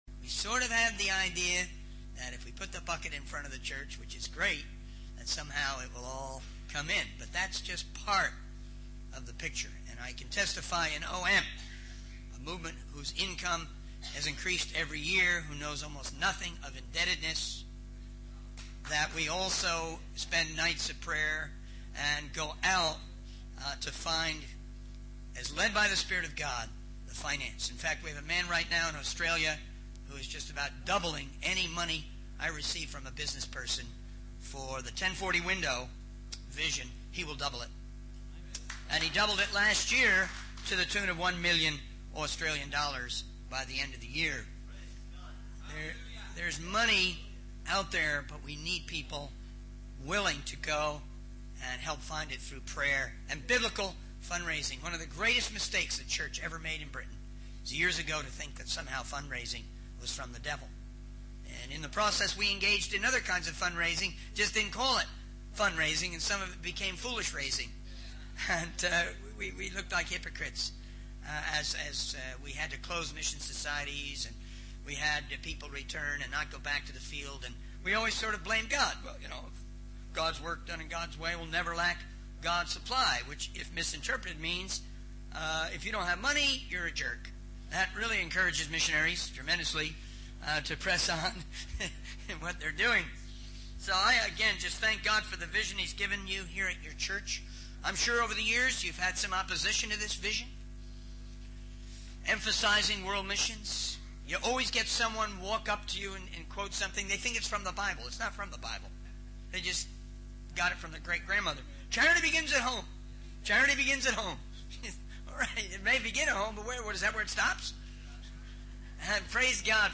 In this sermon, the speaker emphasizes the importance of being either hot or cold in one's faith, rather than being lukewarm. The speaker also highlights the strong emphasis on unity and friendship within the organization Operation Mobilization (OM), which is described as a family. The sermon mentions the goal of bringing 90,000 people who have been on OM into the global partners program for world missions.